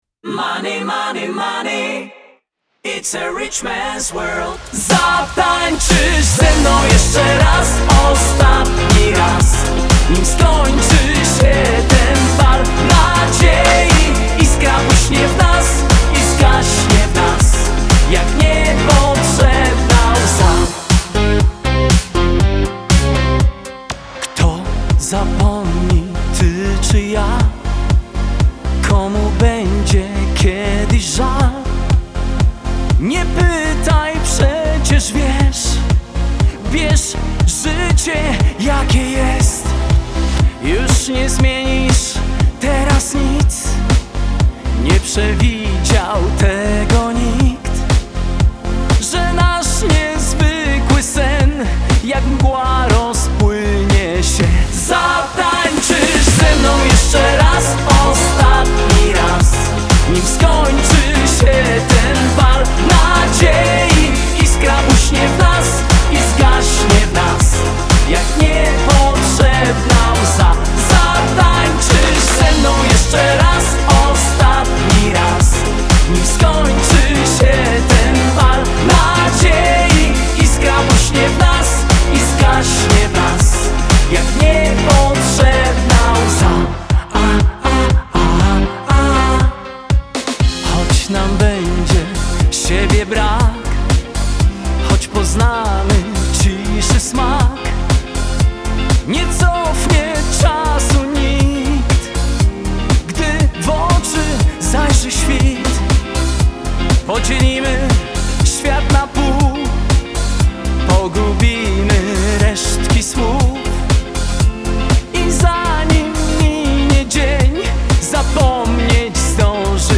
Singiel (Radio)
Współczesna, odświeżona i nowa wersja wielkiego hitu